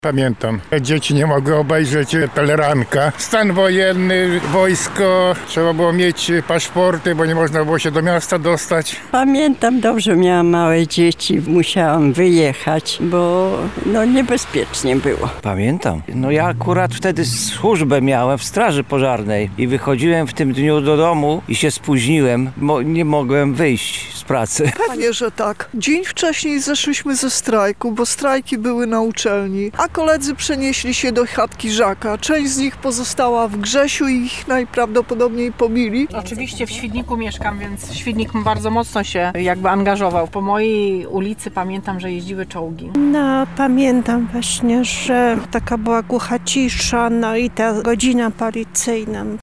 Zapytaliśmy dziś przechodniów, czy pamiętają ten dzień i jak wspominają ten okres w swoim życiu:
sonda stan wojenny
sonda-stan-wojenny.mp3